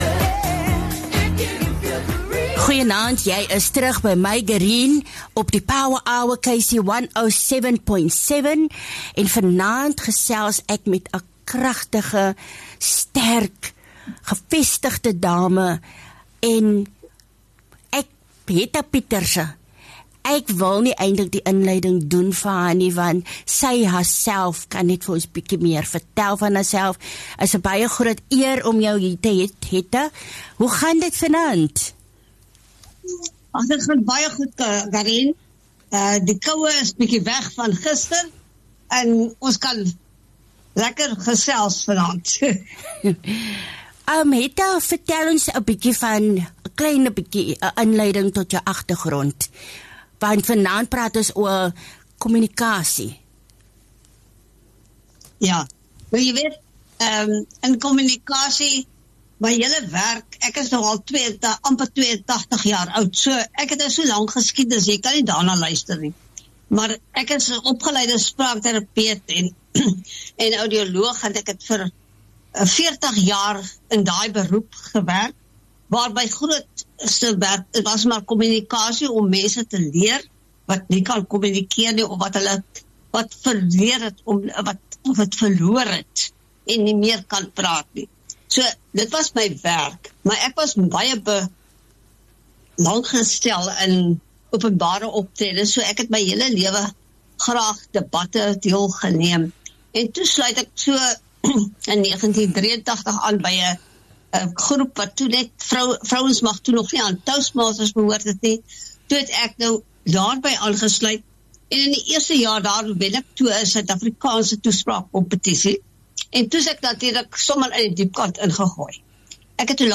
Onderhoud